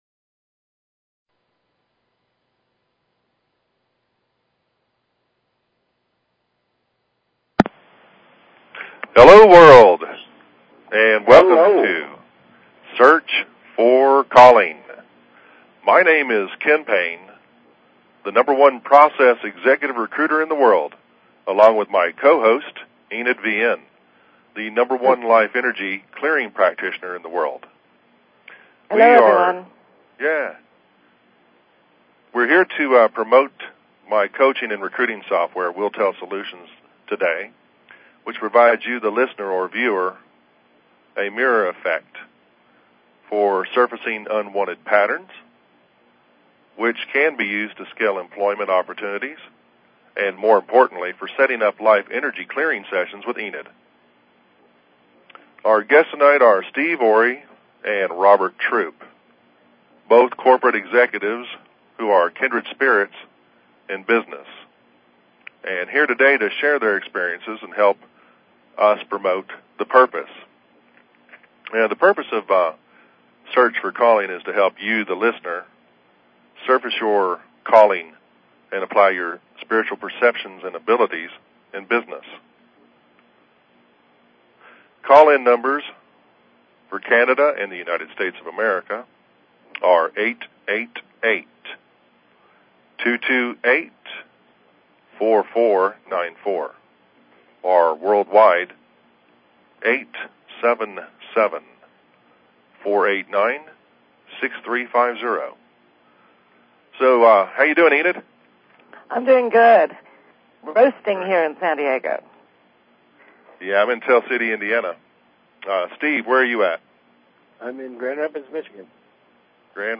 Talk Show Episode, Audio Podcast, Search_for_Calling and Courtesy of BBS Radio on , show guests , about , categorized as